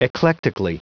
Prononciation du mot eclectically en anglais (fichier audio)
Prononciation du mot : eclectically